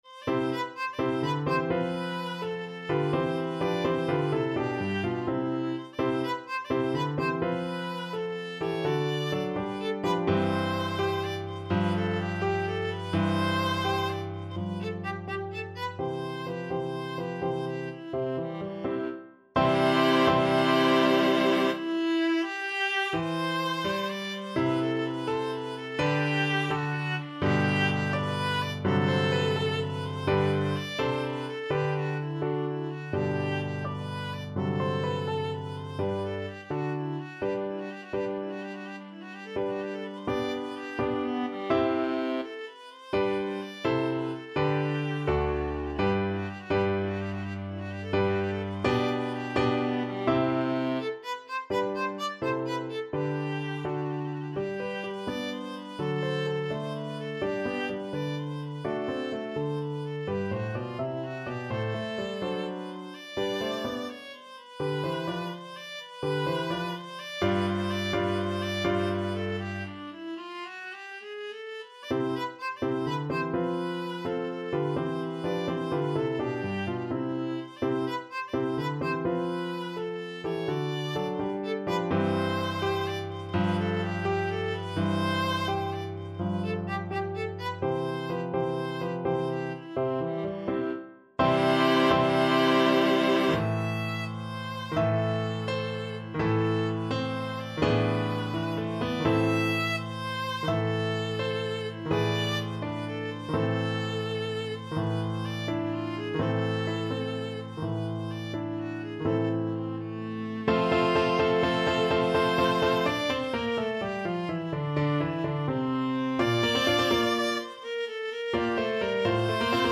Viola
6/8 (View more 6/8 Music)
C4-E6
C major (Sounding Pitch) (View more C major Music for Viola )
Grazioso .=84
Classical (View more Classical Viola Music)